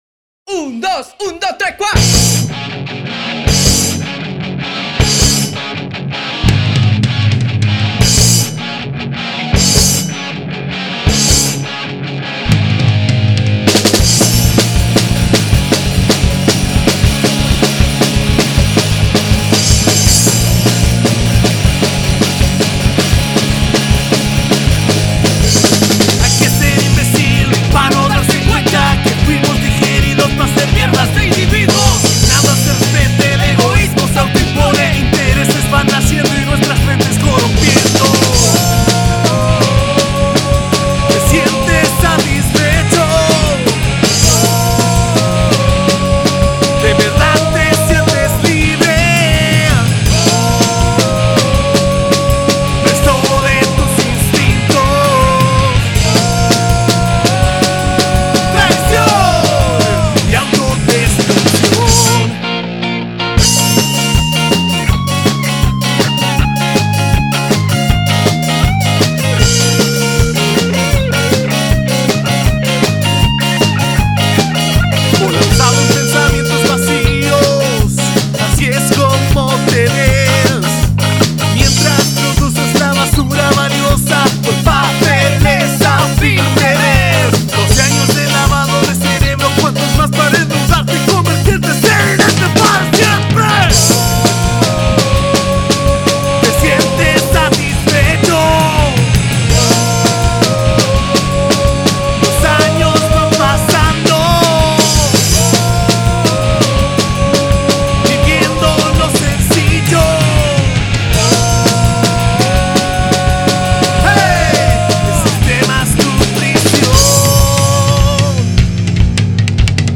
LEAD GUITAR
VOZ, TECLADO
BAJO, VOCES
RHYTHM GUITAR, VOCES
BATERÍA
Rock Alternativo